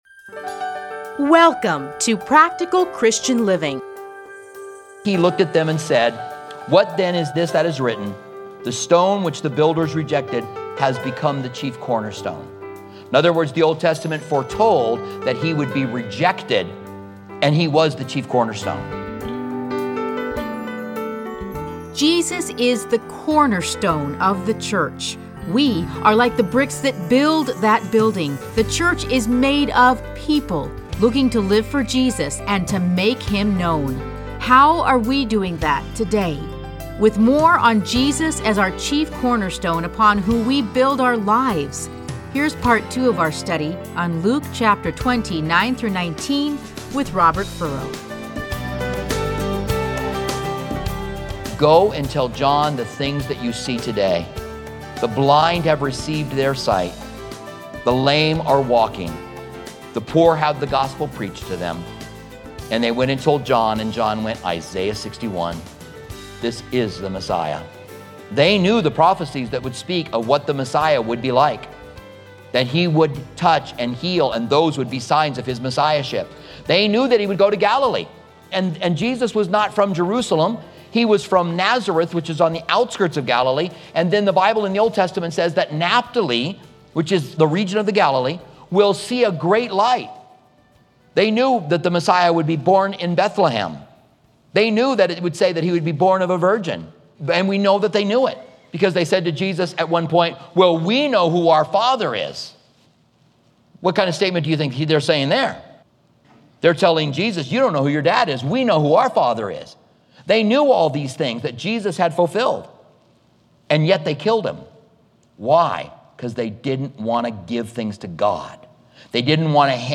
Listen to a teaching from Luke 20:9-19.